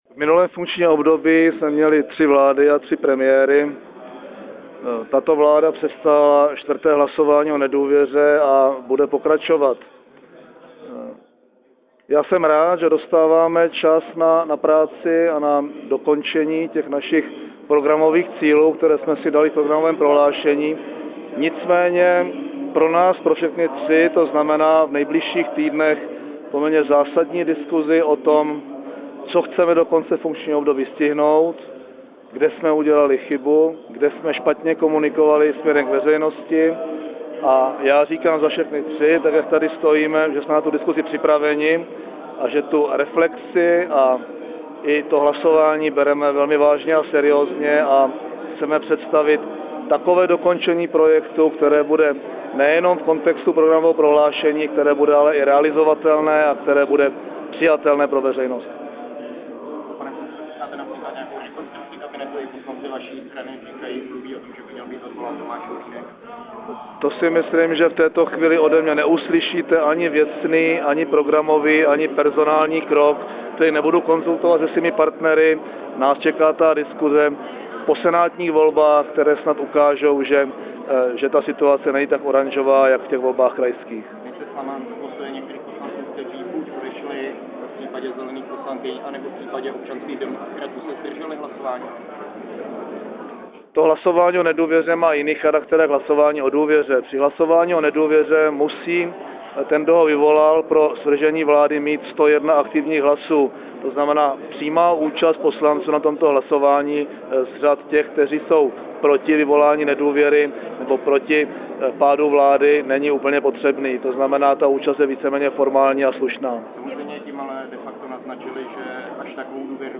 Komentář představitelů vládní koalice nabízí níže připojená příloha
Představitelé vlády k výsledku hlasování v Poslanecké sněmovně